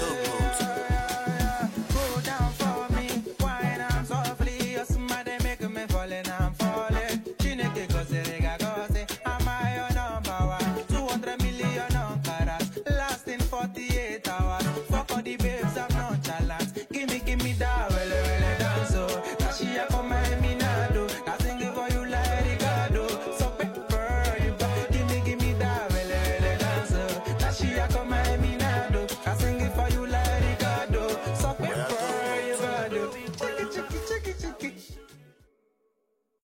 Fuji Music
Yoruba Fuji Sounds